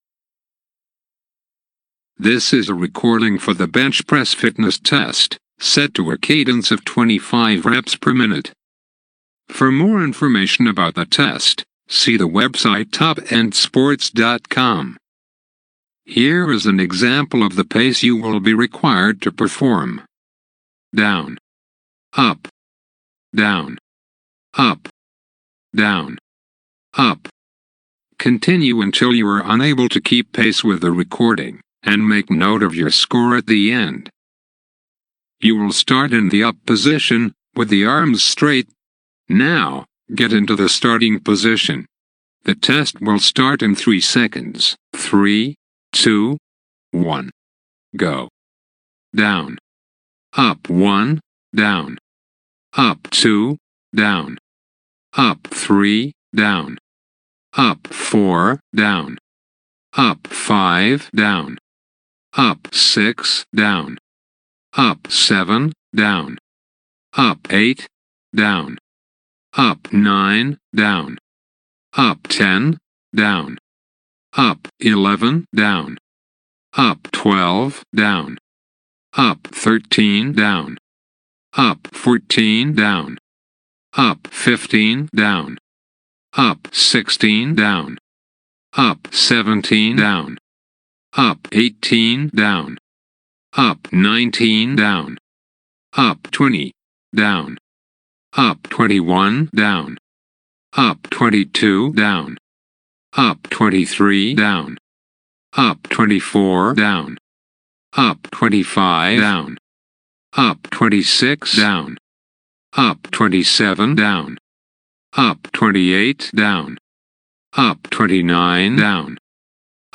The test is at a rate of 25 complete reps per minute (1 bench press every 2.4 seconds), to be used when conducting one of the cadence bench press tests described on this site (e.g. Bench Press Beep and the Relative Bench Press Test).
This audio track counts your bench press at a cadence of 25 reps per minute, for two minutes.
bench-press-cadence-test.mp3